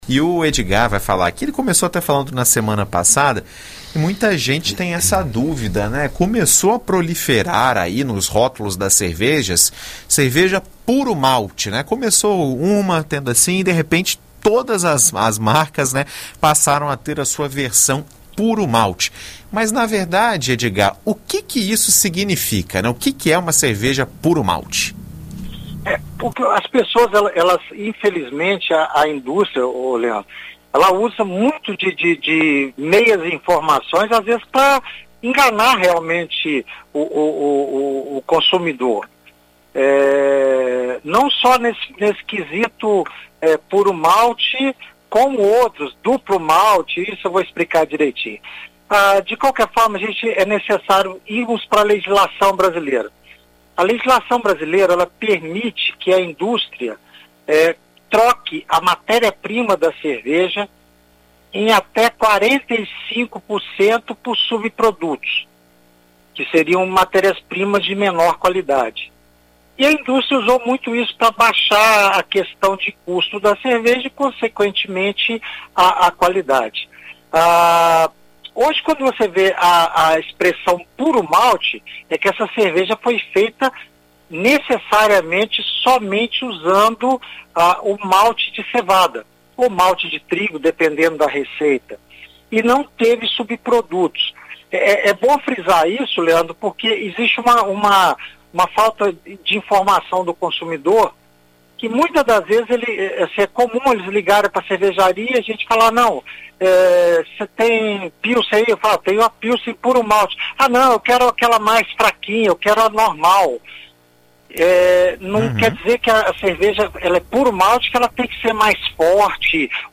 Na BandNews FM, mestre cervejeiro explica sobre o processo de produção dessas cervejas e fala sobre as classificações do malte